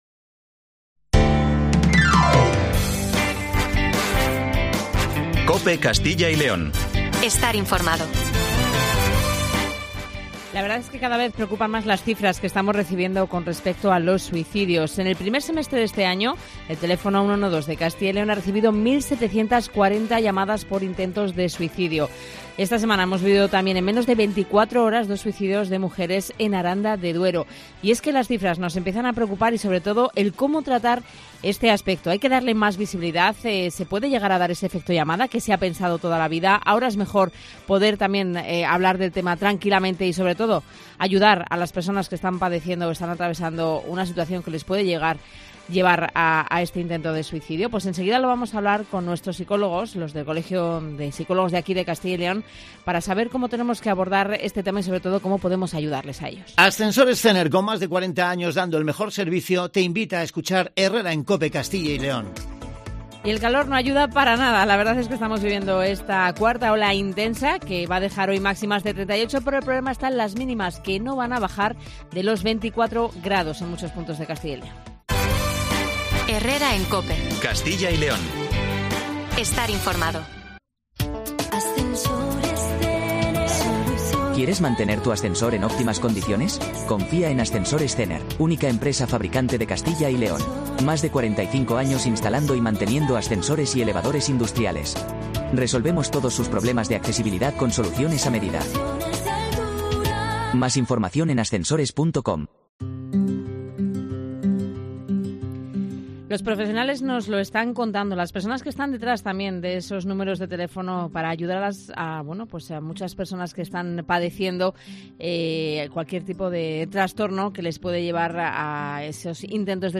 AUDIO: Aumenta el número de suicidios en nuestro país, intentamos conocer porqué está sucediendo y cómo podemos prevenirlo charlando con el psicólogo...